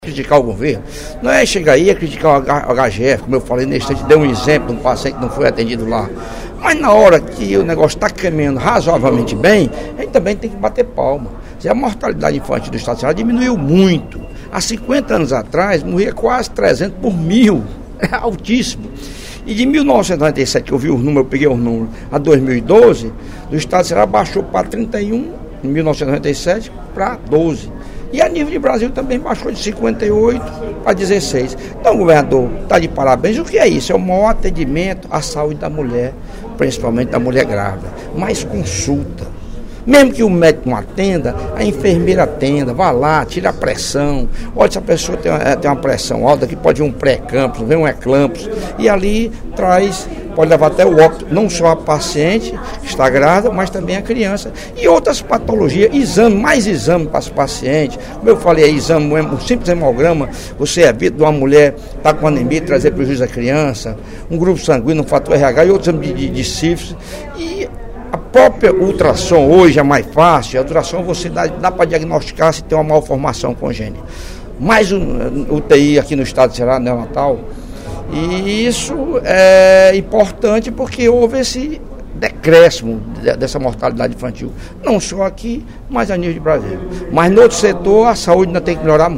O deputado Lucílvio Girão (PMDB) comemorou, no primeiro expediente da sessão plenária desta quinta-feira (06/06), a redução das taxas da mortalidade infantil no Estado do Ceará.